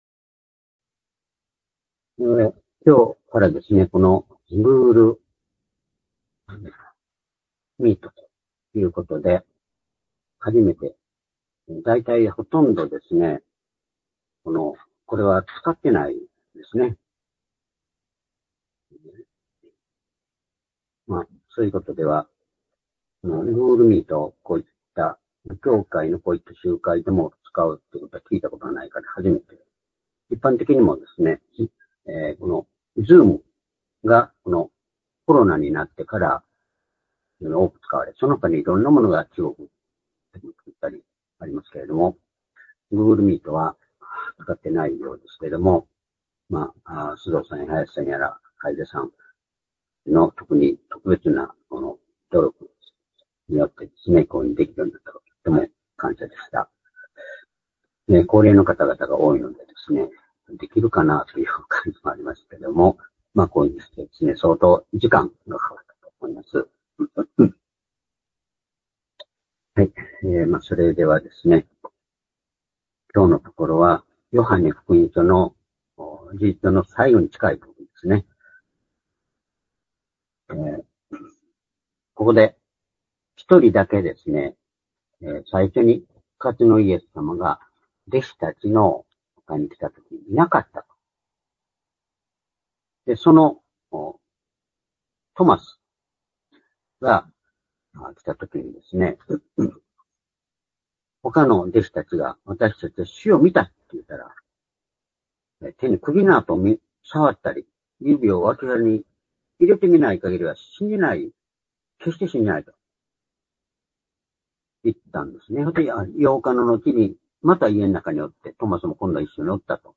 主日礼拝日時 ２０２５年５月４日（主日礼拝） 聖書講話箇所 「信じない者にならず、信じる者に」 ヨハネ２０章２４節～２９節 ※視聴できない場合は をクリックしてください。